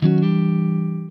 SLIDECHRD4.wav